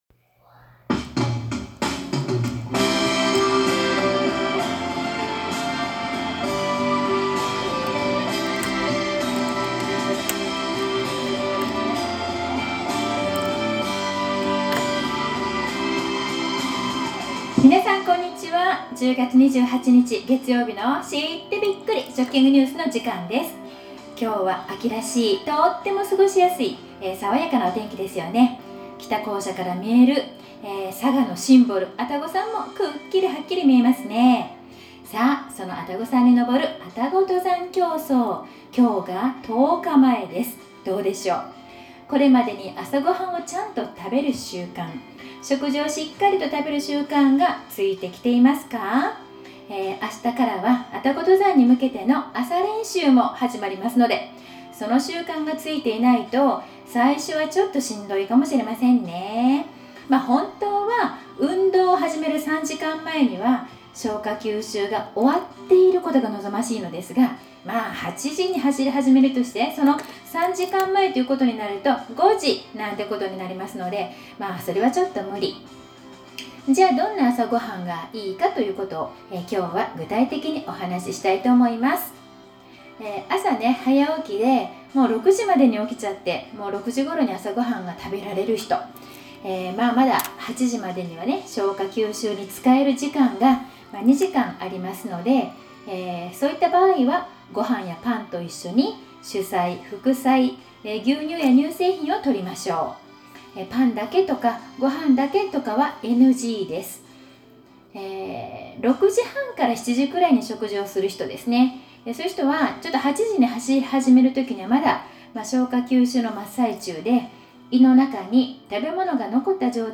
１０月２８日食育放送〜愛宕登山競走朝練の日の朝食